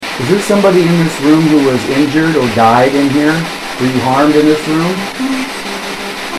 Spirit Box Clip 2
About the clip: Another great spirit response through the P-SB11 spirit box! This clear voice answered my question, immediately during the same session as Clip #1: In the basement's "bad" area, where one of our investigators told me she has sensed that some sort of violence took place in the past.
Double CD Audio Quality CD Audio Quality MP3 (Compressed) The voice of a young female replies, "Yeah" or "Yes".